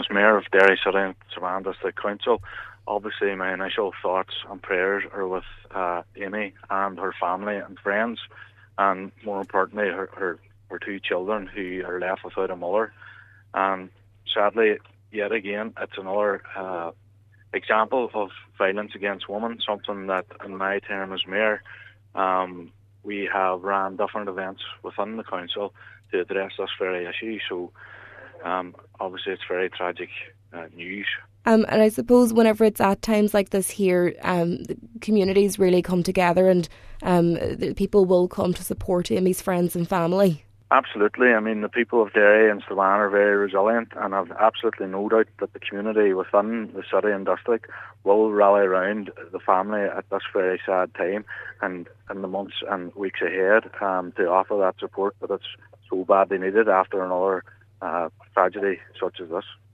Mayor of Derry City and Strabane District Council, Cllr Ruairí McHugh, extended his condolences:
derry-mayor.mp3